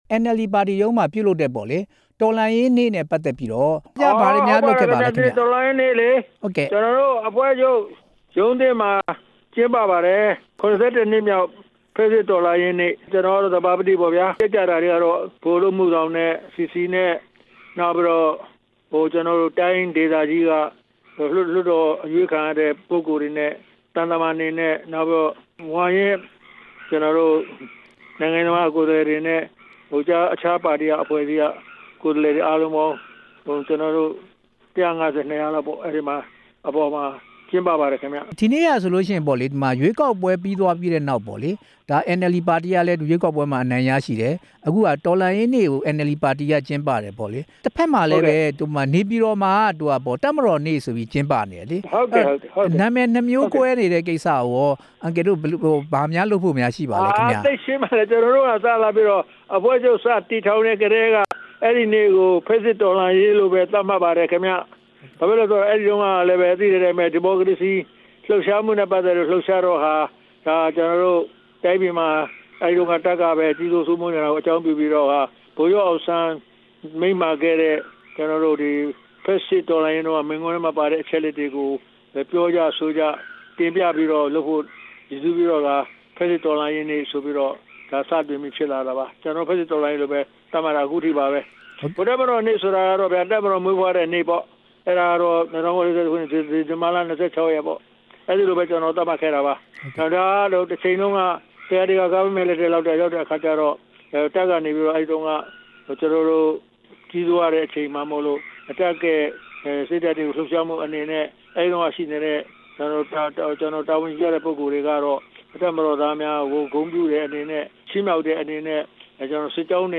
ဖက်ဆစ်တော်လှန်ရေးနေ့ အခမ်းအနားအကြောင်း မေးမြန်းချက်